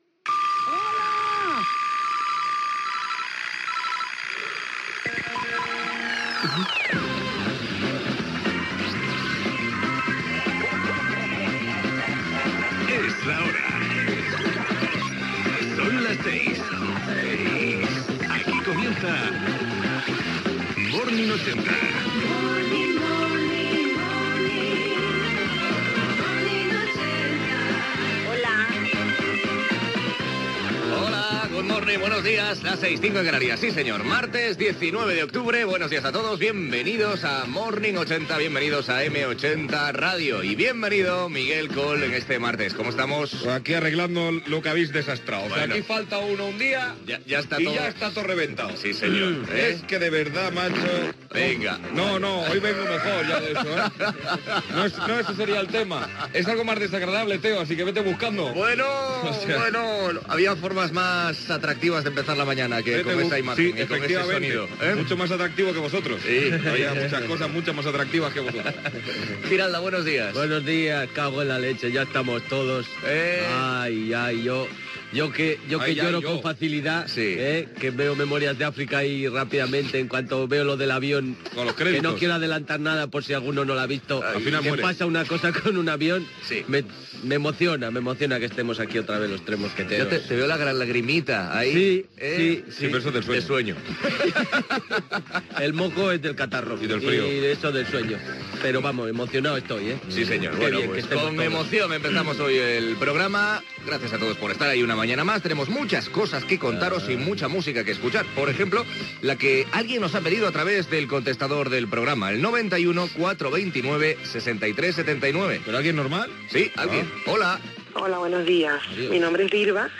Careta del programa, data, salutació a l'equip del programa, trucada al contestador i tema musical
FM